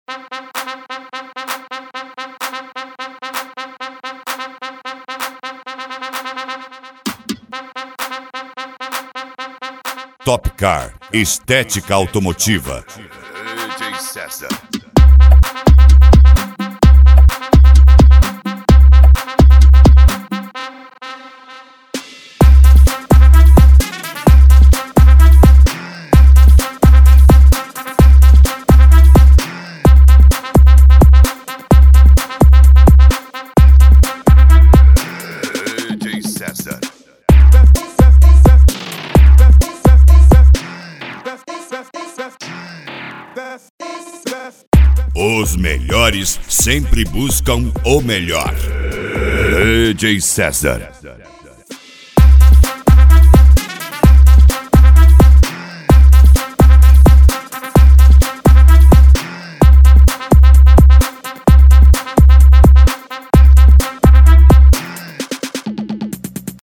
Funk
Mega Funk
Melody
Modao